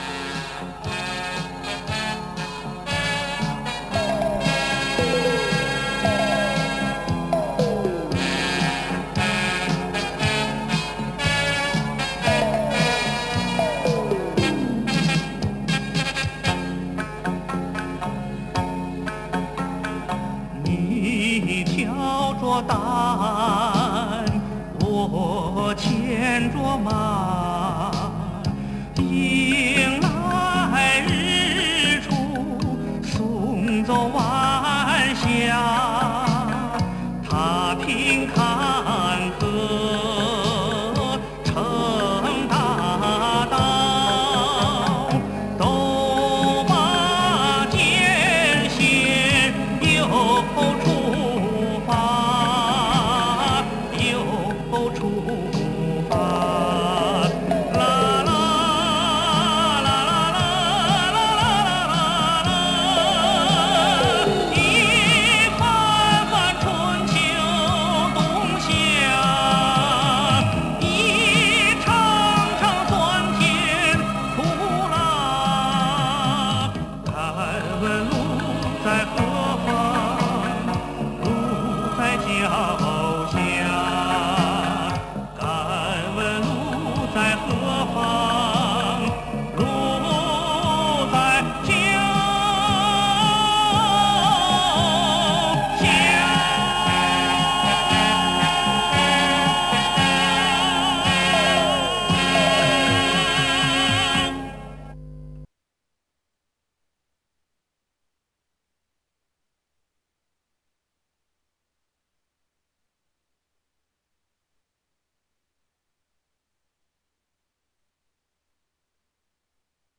Comments: I recorded this myself, so it's a .wav file.